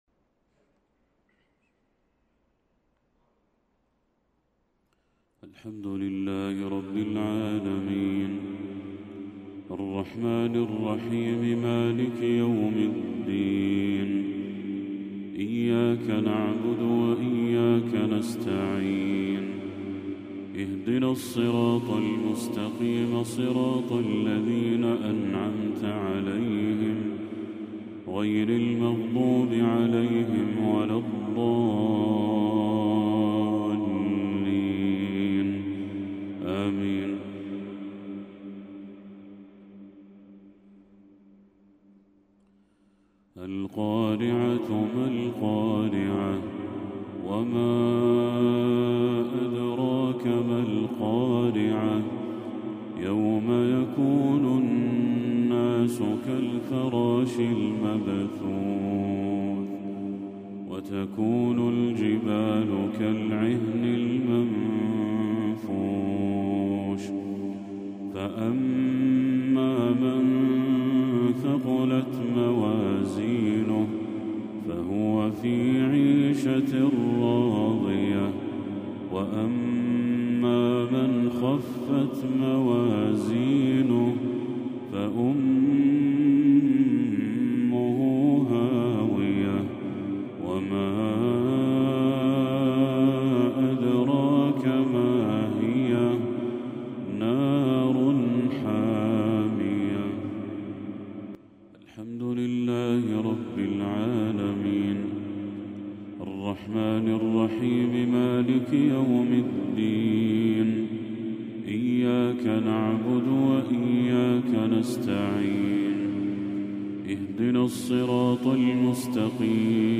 تلاوة للشيخ بدر التركي سورتي القارعة والتكاثر | مغرب 22 ربيع الأول 1446هـ > 1446هـ > تلاوات الشيخ بدر التركي > المزيد - تلاوات الحرمين